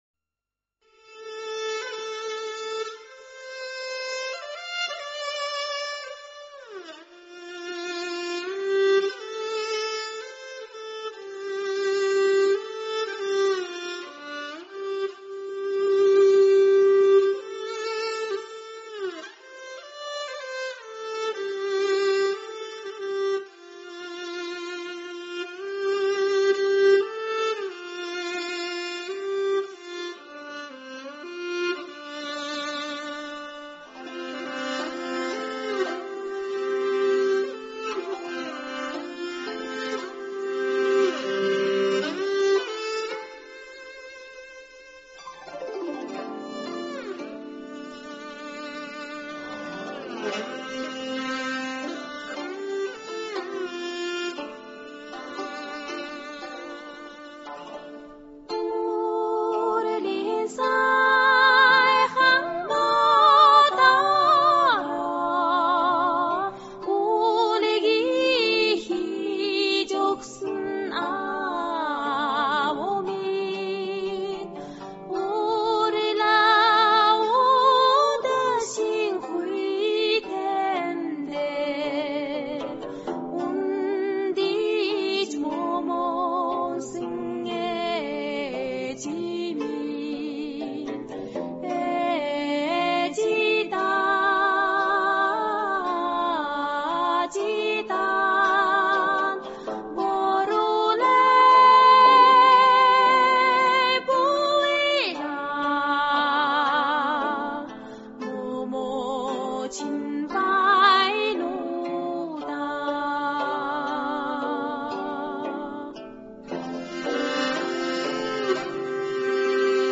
大自然气息和辽阔的空间感，让你置身于蓝天白云之间，仿佛令你走进了广阔的草原。
女声二重唱